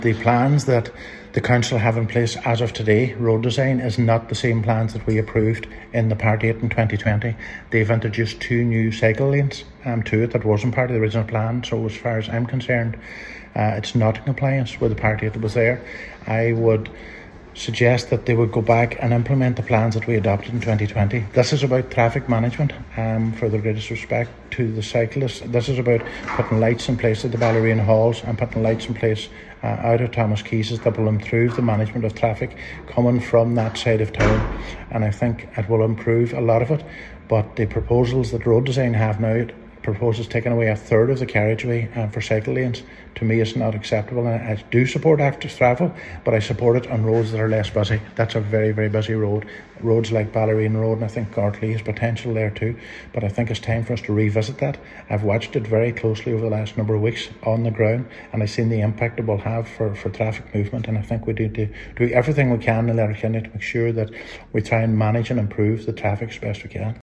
He says it’s vital active travel plans in Letterkenny are developed to focus more on traffic movement: